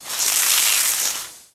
Indoor Fire Sprinklers Spray Water On Metal Surface